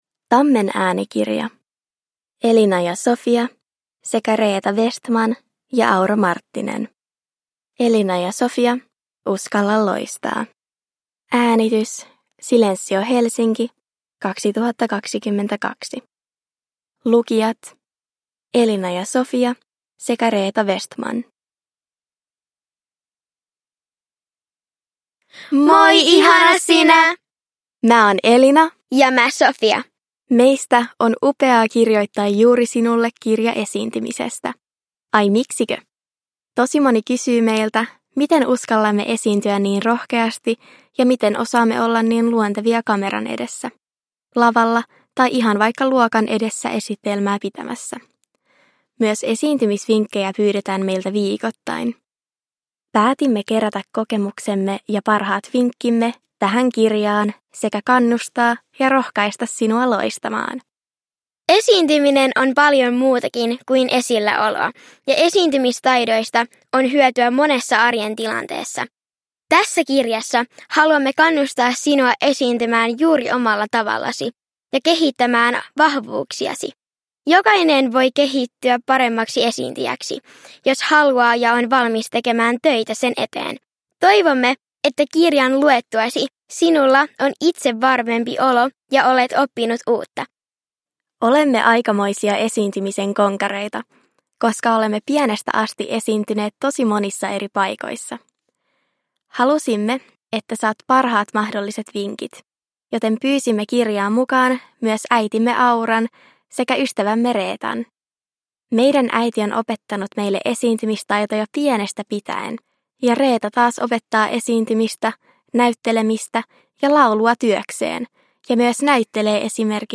Uskalla loistaa! – Ljudbok – Laddas ner